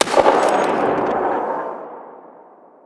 AntiMaterialRifle_far_03.wav